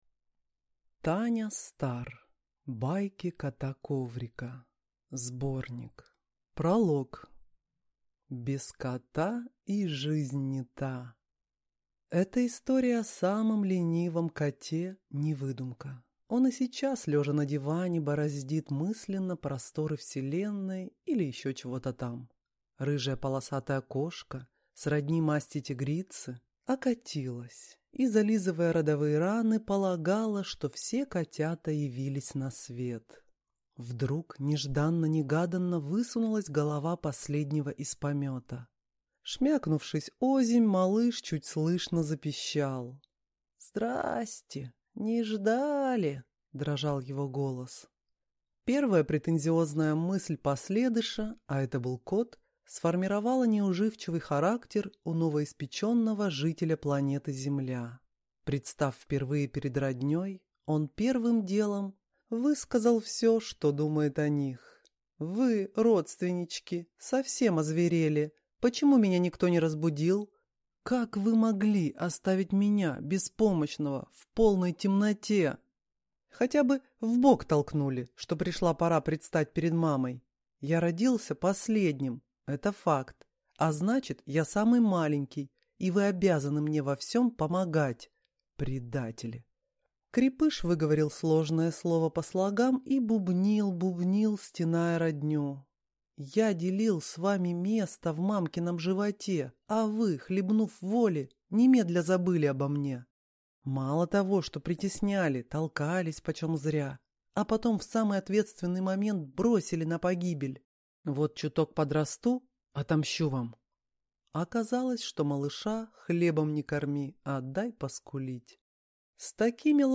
Аудиокнига Байки кота Коврика. Сборник | Библиотека аудиокниг